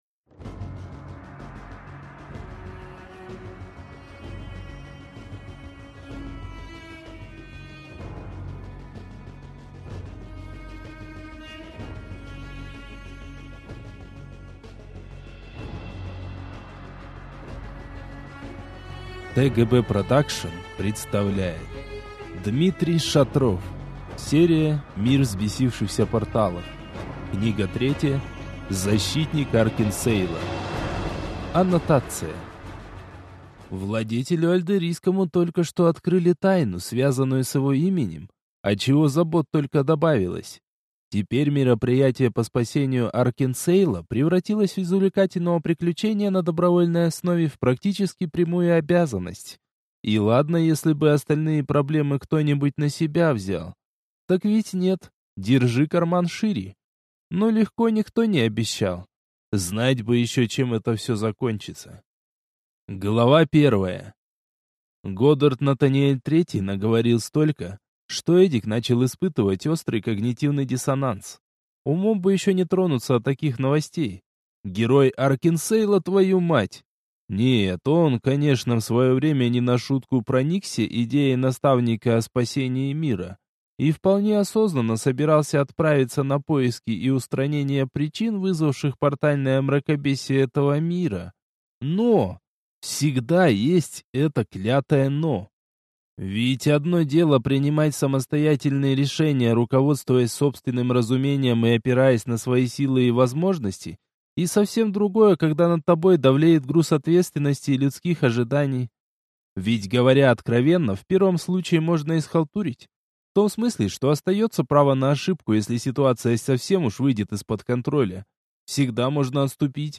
Аудиокнига Защитник Аркенсейла | Библиотека аудиокниг